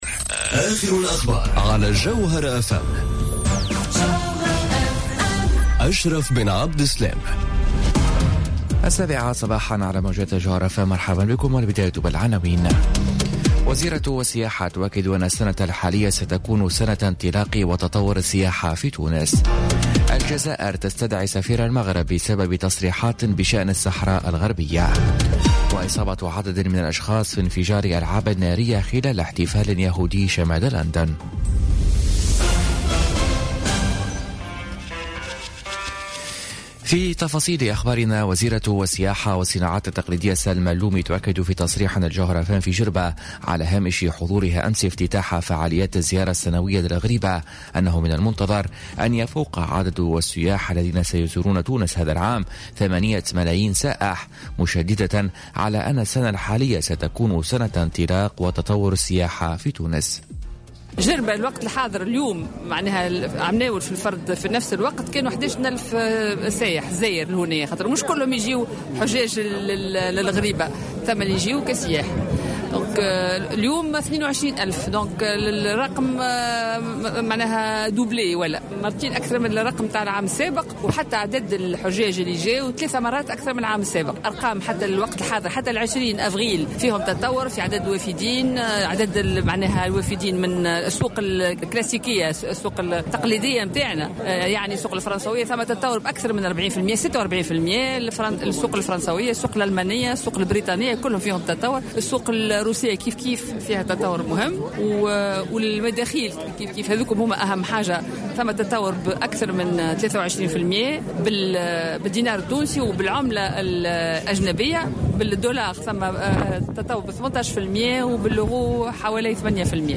نشرة أخبار السابعة صباحا ليوم الخميس 3 ماي 2018